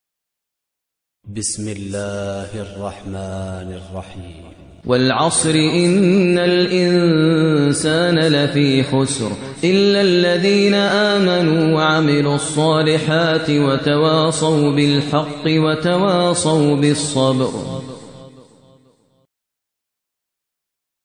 ترتیل سوره عصر با صدای ماهر المعیقلی